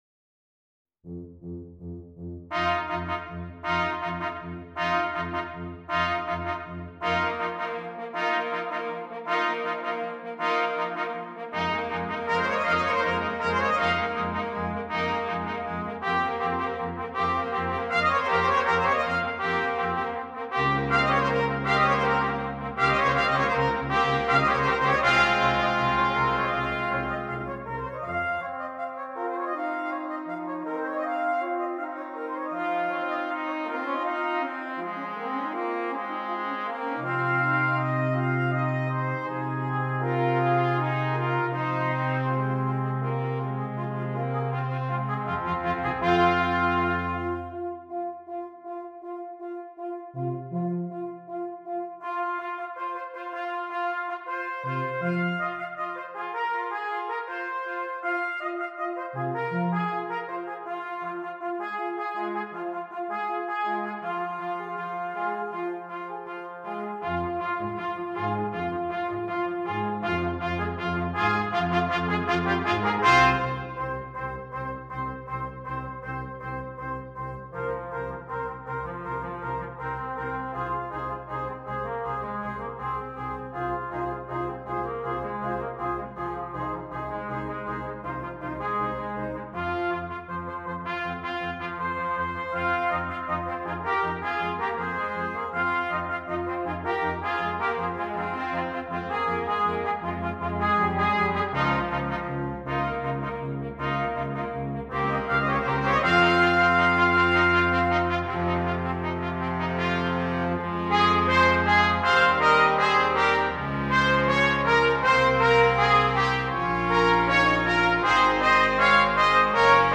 Brass Quintet
introduction to minimalist music for younger ensembles